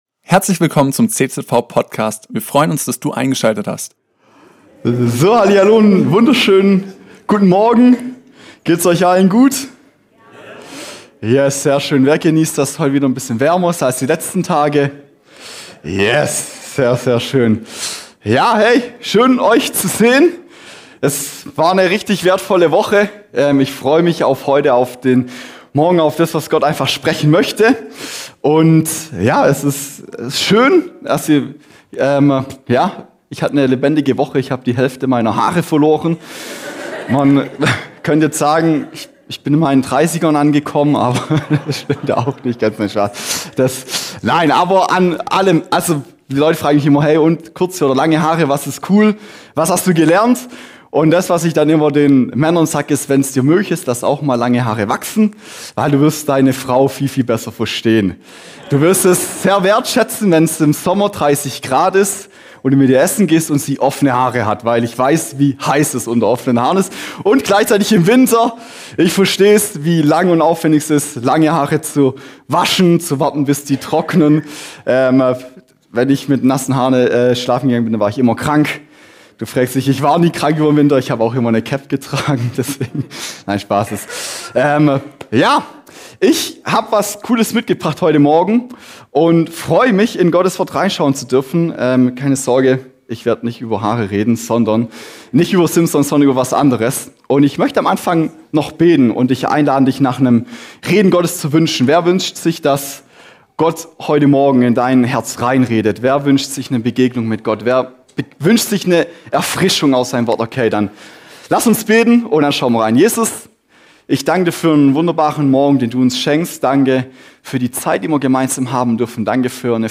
Predigt zum Text "Die zwölf Spione" (4. Mose 13 und 14)